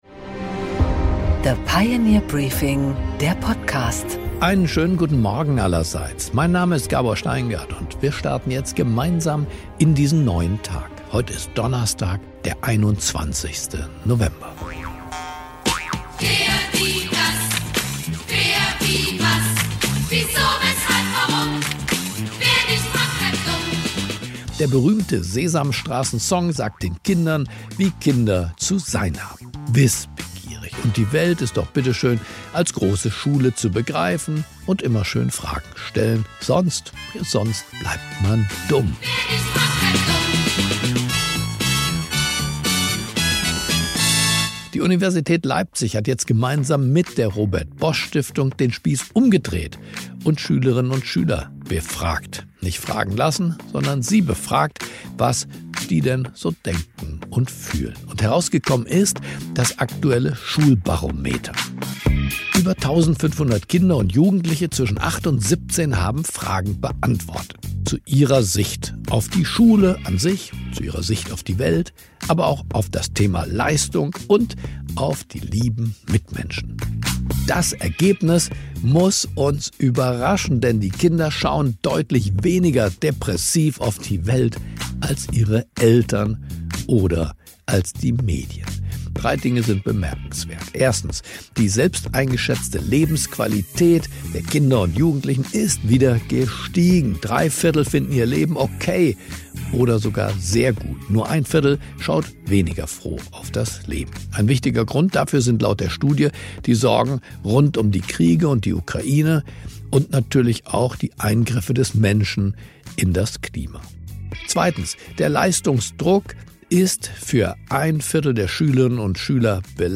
Gabor Steingart präsentiert das Pioneer Briefing